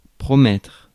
Ääntäminen
IPA: [pʁɔ.mɛtʁ]